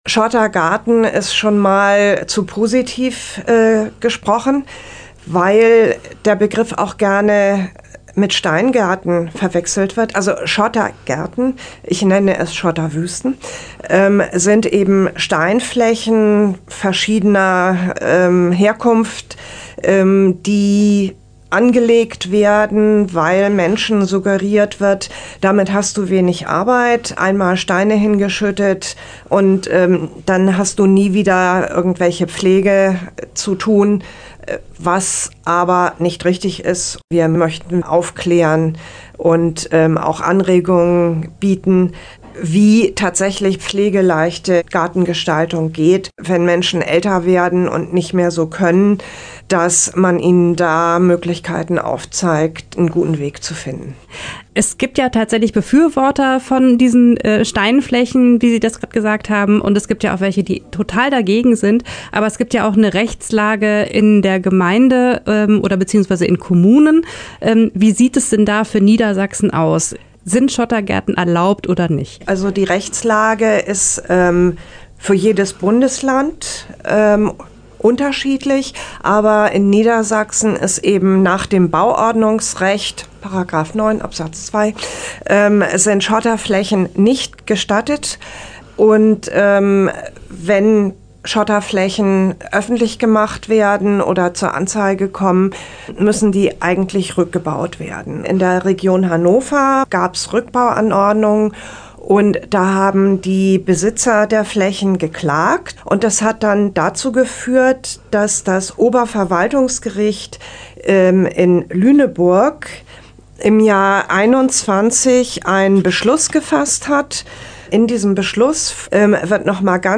Interview-Schottergaerten.mp3